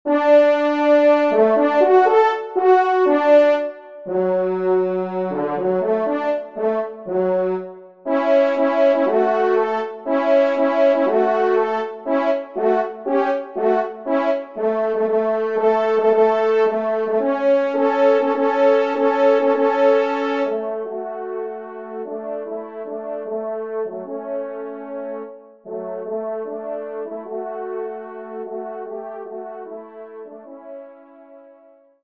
Genre : Divertissement pour Trompes ou Cors